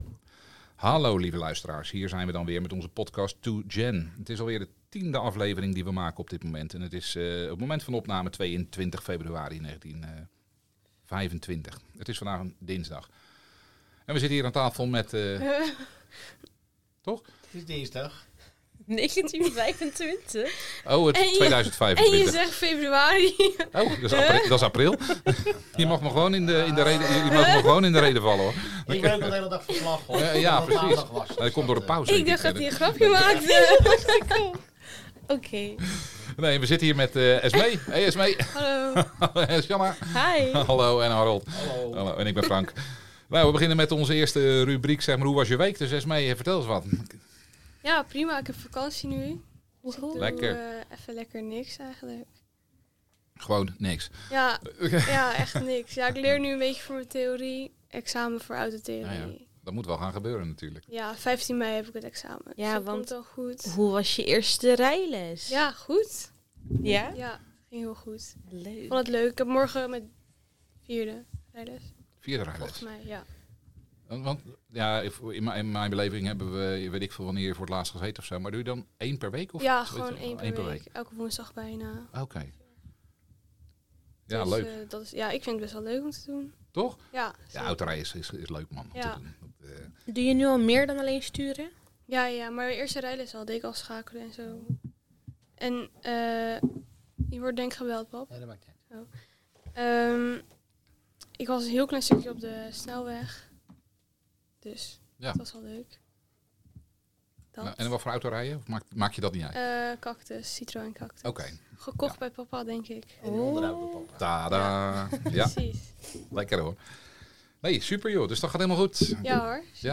2Gen is een podcast met twee generaties. De totale groep voor deze podcast bestaat uit 9 personen. Ze praten over zichzelf, wat ze meemaken en vooral hoe ze de samenleving ervaren. In de podcast vier rubrieken: hoe was je week? GENeratieverschil, leuk nieuws en levenslessen/geleerd (van elkaar).